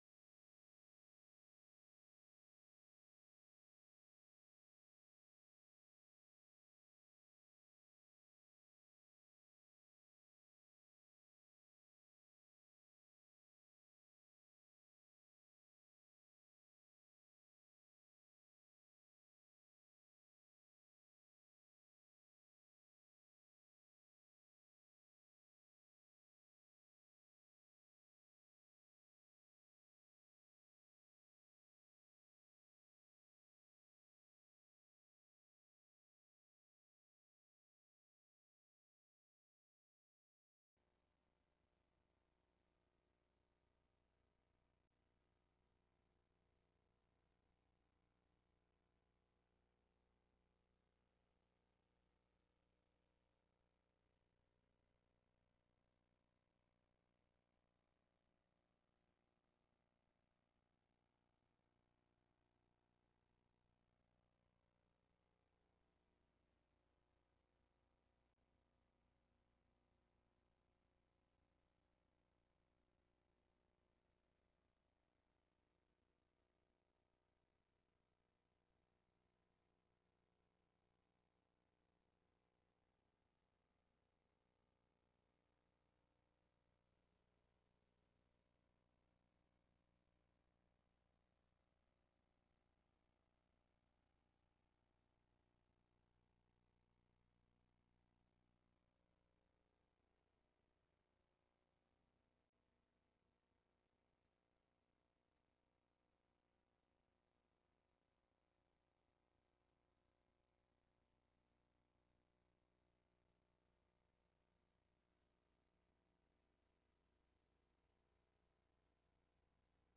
This sermon discusses three important teachings of the 8th Day.